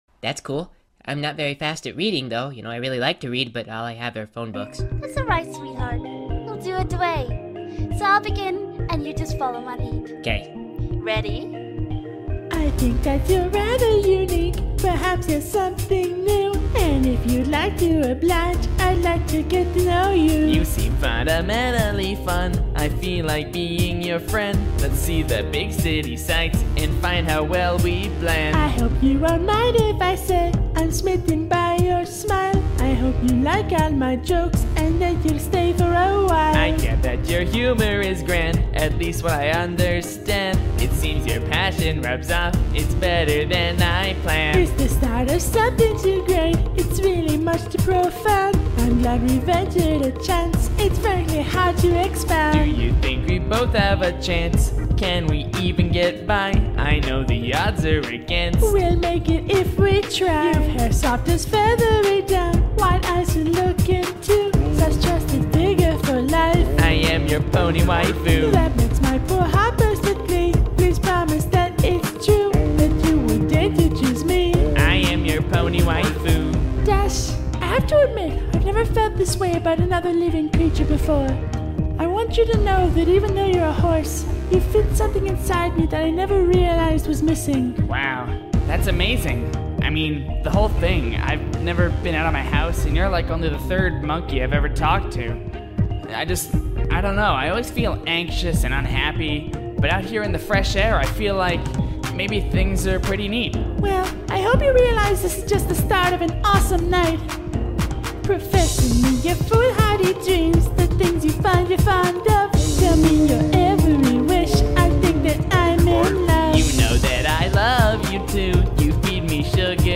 And it's just me singing. And I did a crappy girl voice.